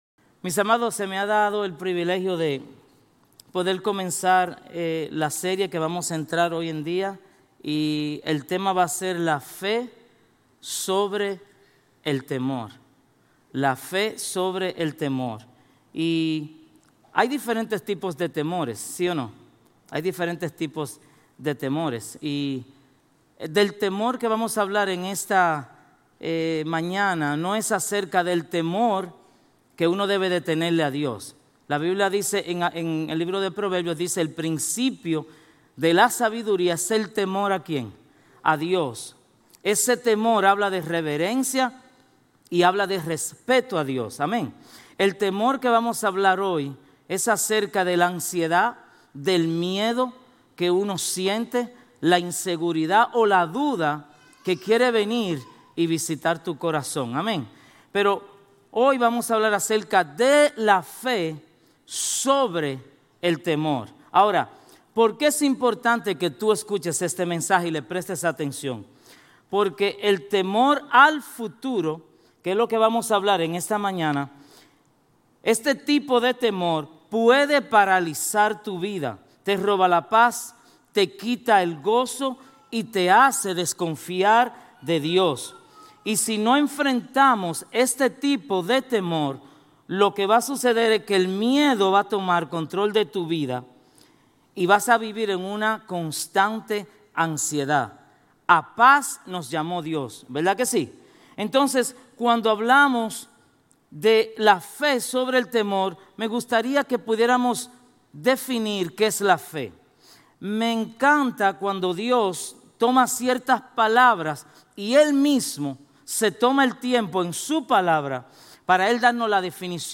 Sermones Grace Español 4_27 Grace Espanol Campus Apr 27 2025 | 00:40:38 1x 00:00 / 00:40:38 Subscribe Share RSS Feed Share Link Embed